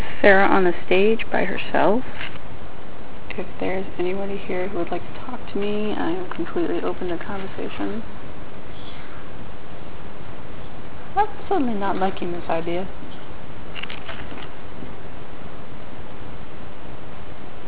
Audio Evidence & Captures
**Please note that we do NOT filter or alter our audio.